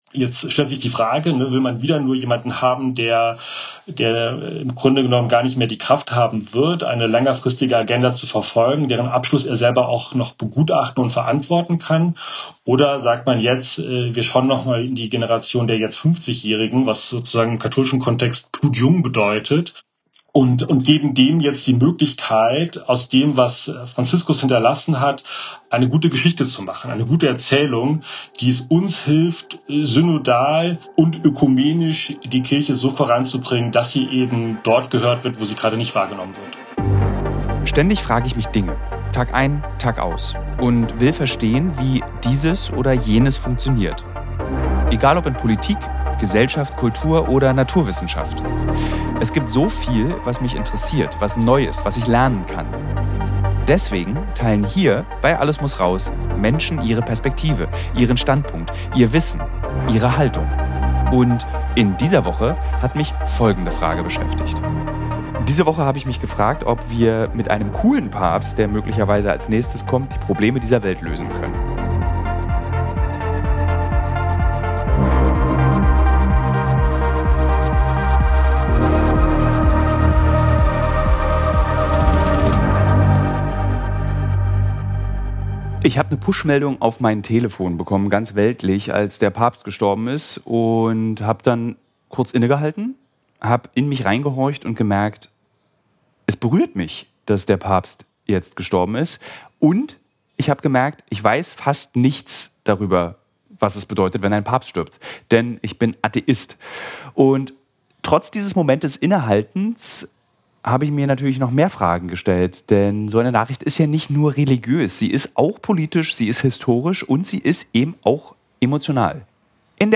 Ein Gespräch über die Rolle und das Erbe von Papst Franziskus und die Herausforderungen der Weltkirche zwischen globalem Süden und europäischem Bedeutungsverlust.